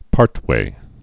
(pärtwā)